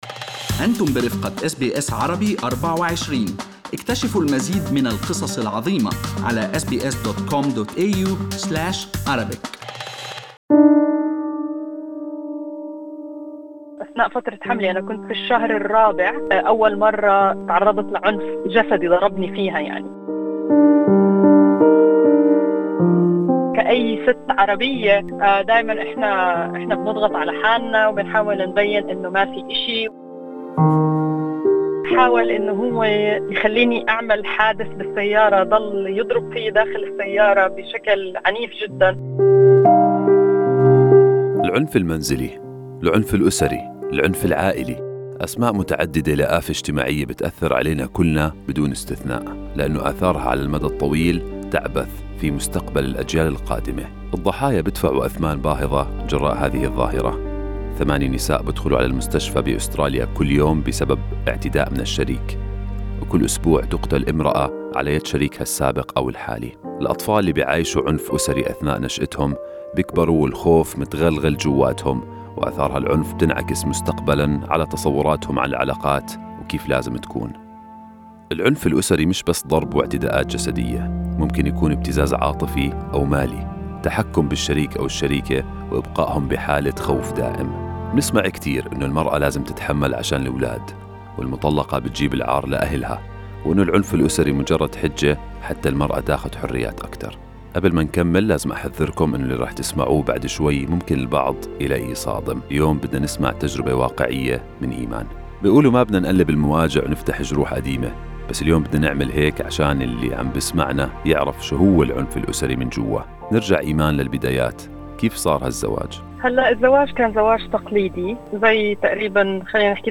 "كان يضربني وأنا حامل": ناجية من العنف المنزلي تروي تجربتها المروّعة